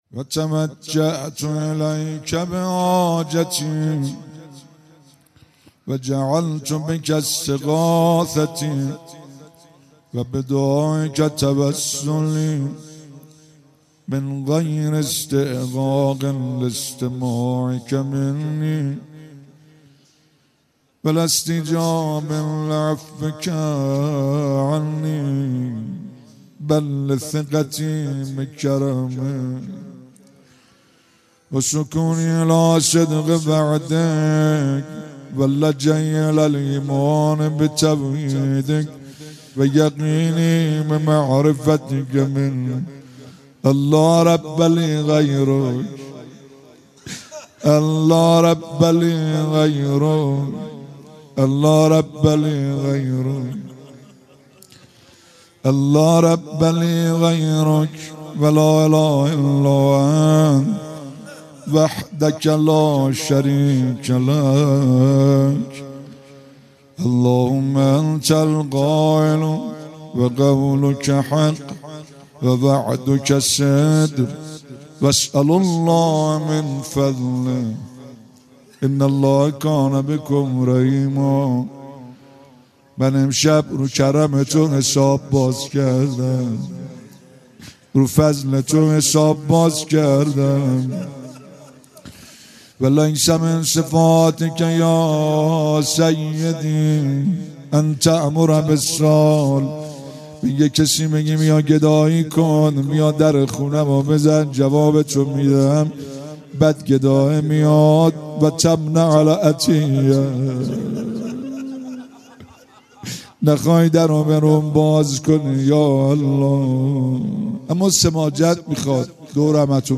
در حرم حضرت معصومه سلام الله علیها با نوای گرم